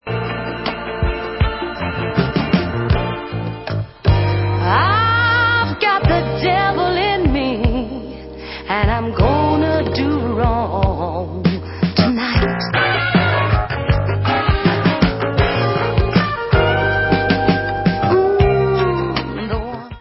Dance/Soul